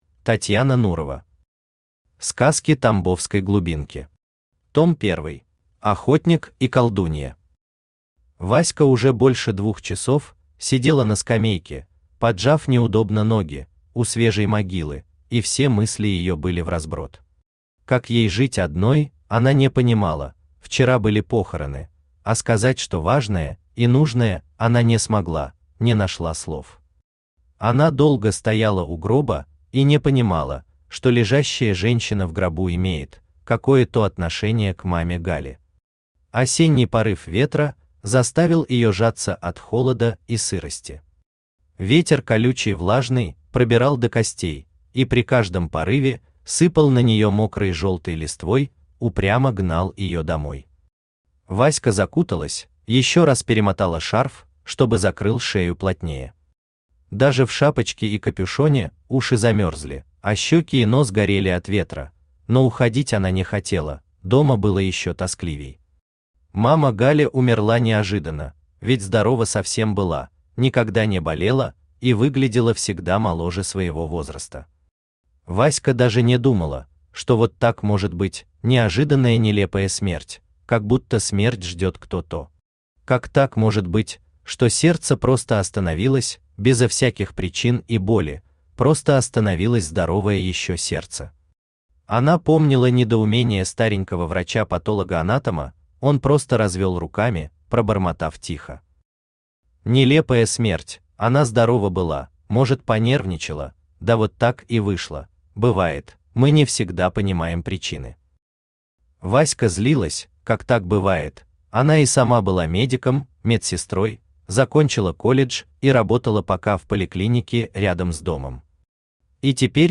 Том первый Автор Татьяна Анатольевна Нурова Читает аудиокнигу Авточтец ЛитРес.